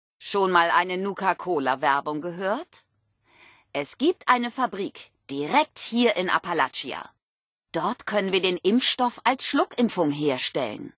Wastelanders: Audiodialoge